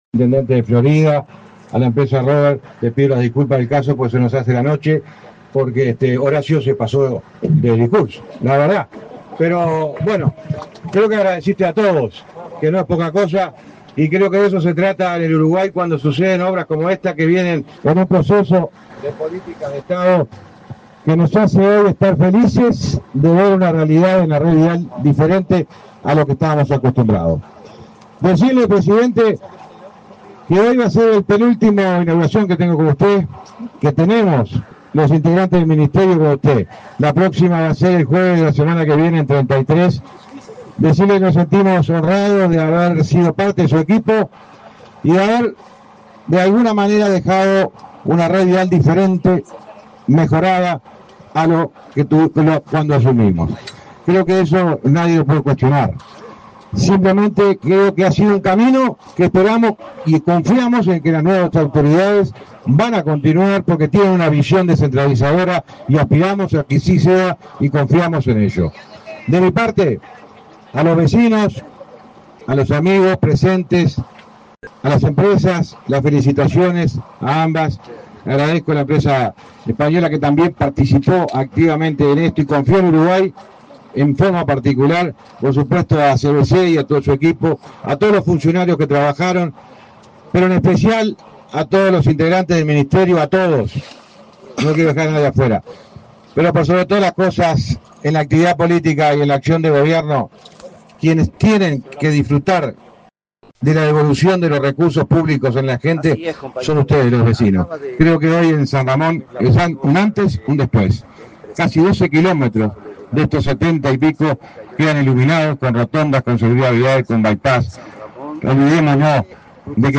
Palabras del titular del MTOP, José Luis Falero
Palabras del titular del MTOP, José Luis Falero 20/02/2025 Compartir Facebook X Copiar enlace WhatsApp LinkedIn El presidente de la República, Luis Lacalle Pou, participó, junto con autoridades del Ministerio de Transporte y Obras Públicas (MTOP), en la inauguración de obras de rehabilitación de 78 kilómetros de la ruta n.° 6, en Canelones. En el evento disertó el titular de la citada cartera, José Luis Falero.
Falero acto.mp3